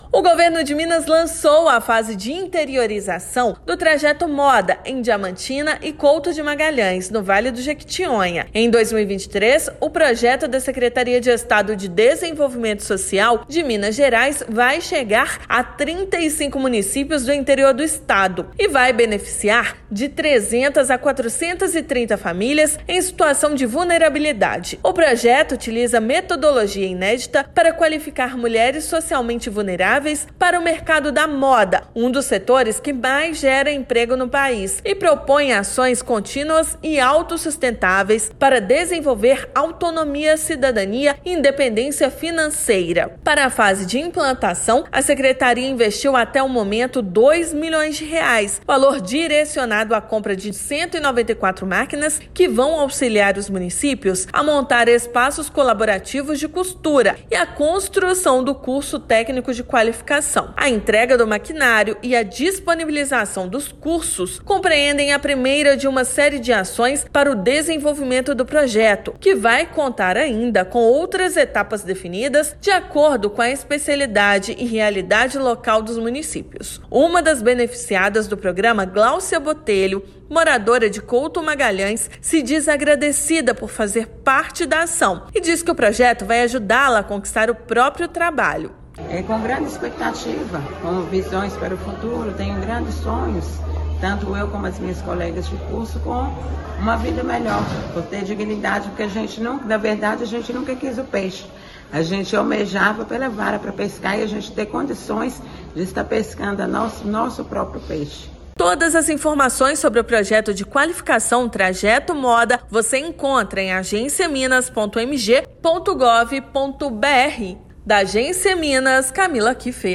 Projeto de qualificação, inserção produtiva e empoderamento feminino chegará a outros 33 municípios, beneficiando até 430 famílias em 2023. Ouça matéria de rádio.